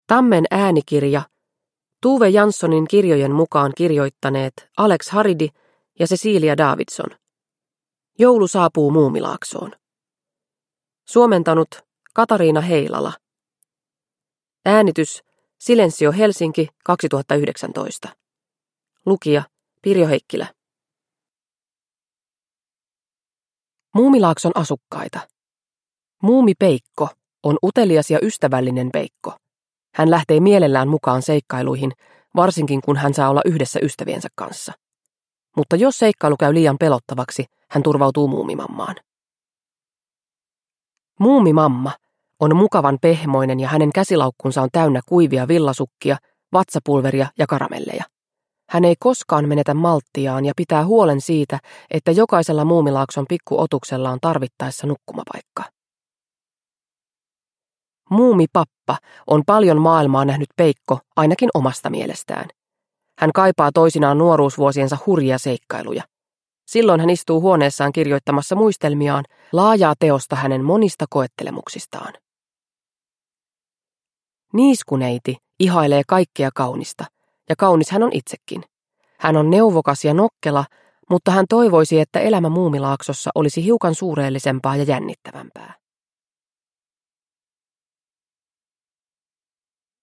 Joulu saapuu Muumilaaksoon – Ljudbok – Laddas ner